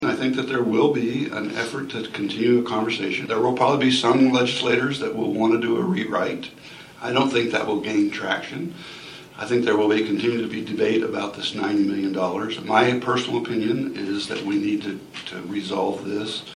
MANHATTAN — The Manhattan-Ogden USD 383 Board of Education held a special work session Wednesday with Kansas lawmakers regarding some of the issues they hope to see brought up in the upcoming legislative session.